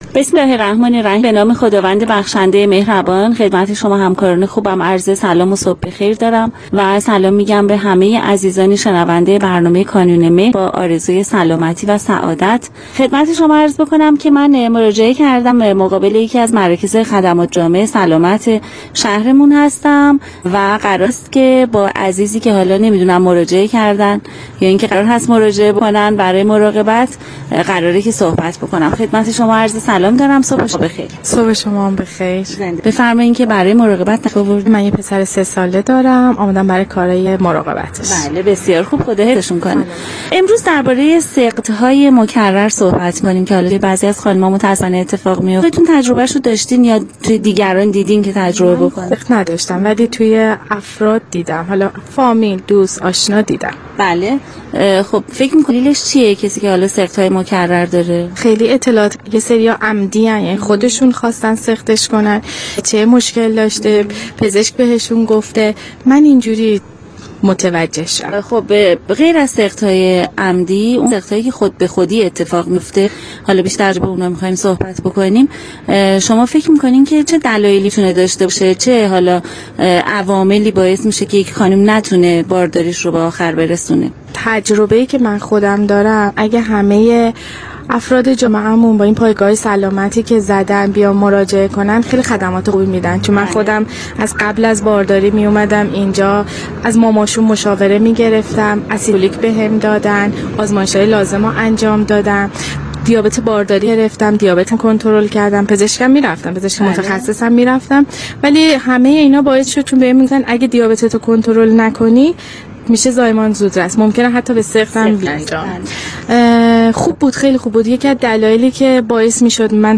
گزارش مردمی در خصوص پیشگیری از سقط
برنامه رادیویی کانون مهر